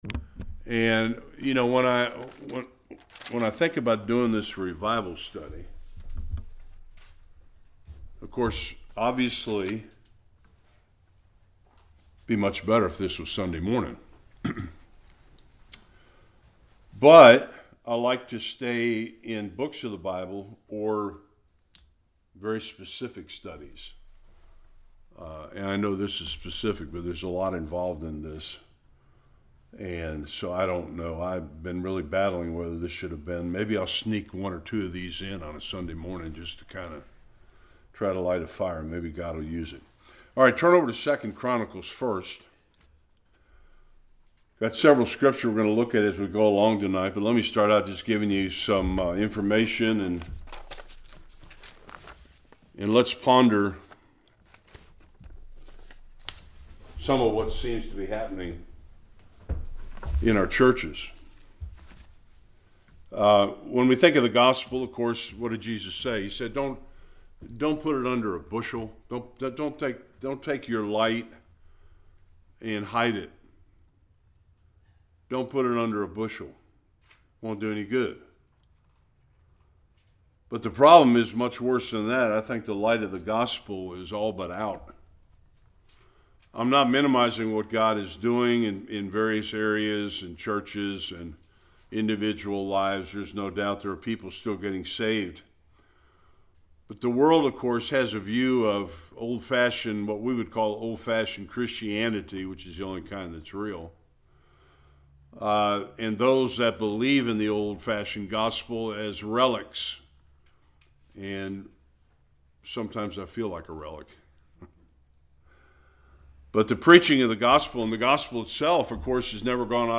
Various Passages Service Type: Bible Study The light of the Gospel is growing dim.